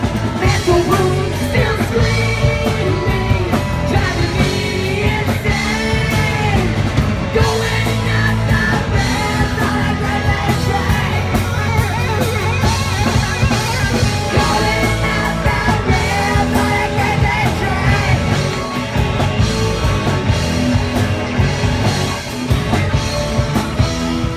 (audio captured from youtube video montage)